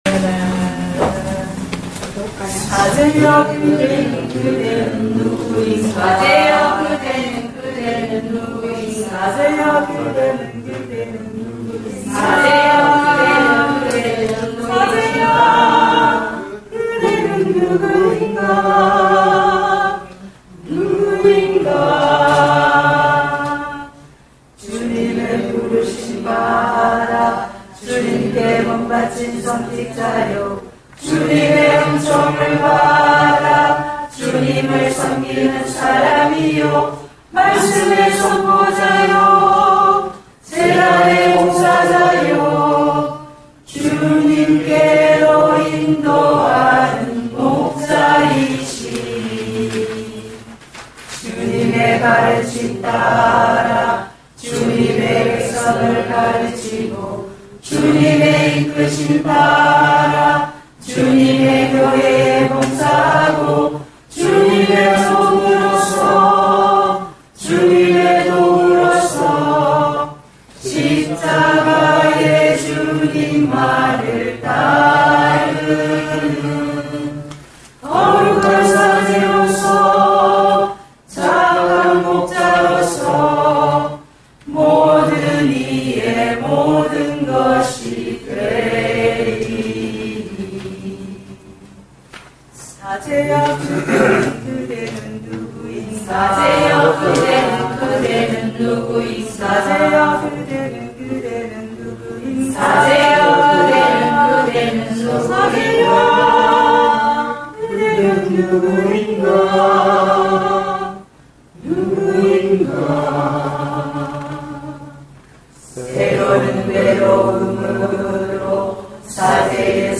흥겨운 오락과 신앙나눔의 시간으로 이어집니다.